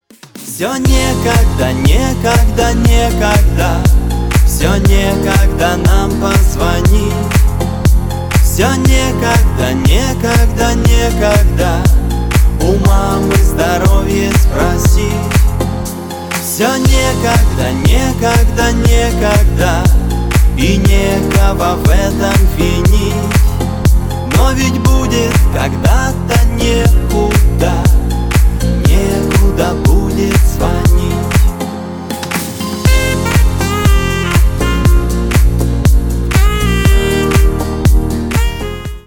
душевные
поп